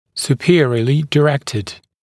[s(j)uː’pɪərɪəlɪ dɪ’rektɪd][с(й)у:’пиэриэли ди’рэктид]направленный вверх
superiorly-directed.mp3